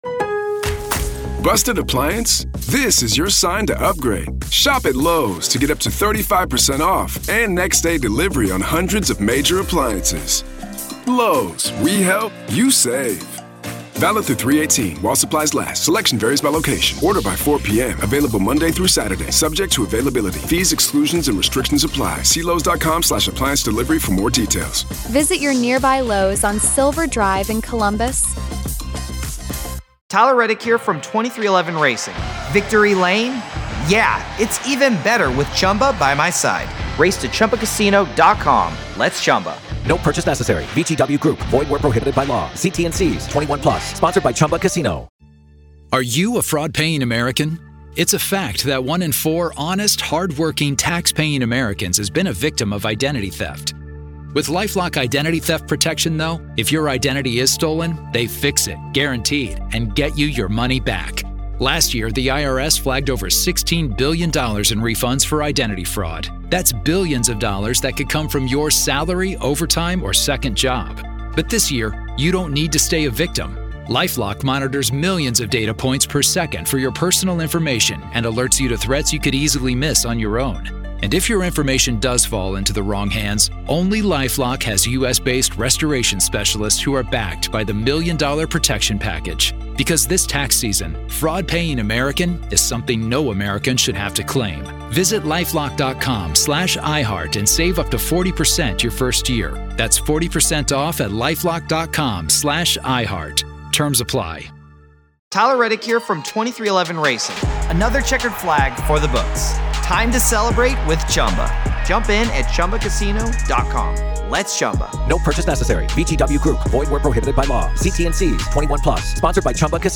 In this first part of our revealing conversation